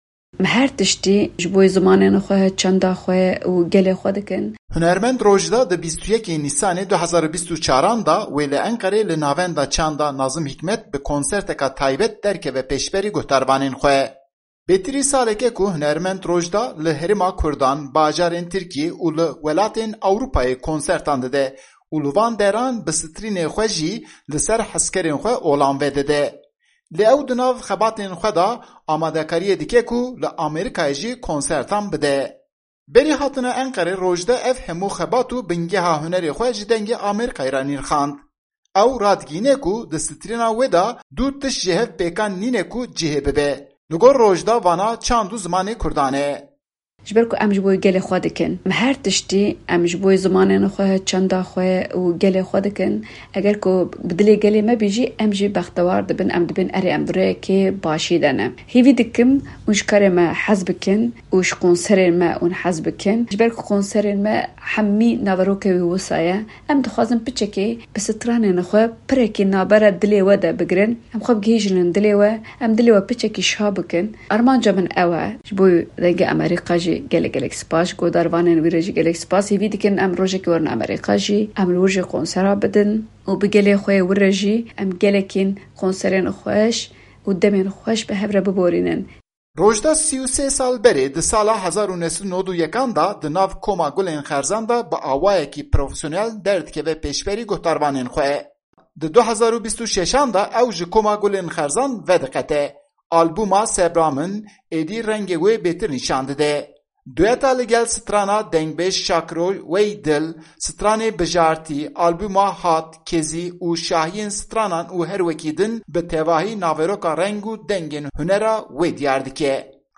Berî hatina Enqerê, Rojda derbarê xebat û bingeha hunera xwe bi Dengê Amerîka re axivî.